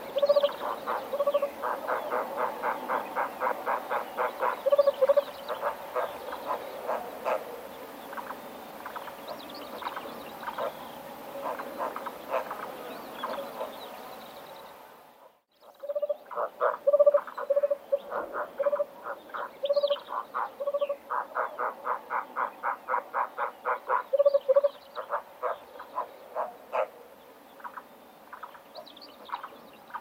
Huppe fasciée - Mes zoazos
huppe-fasciee-1.mp3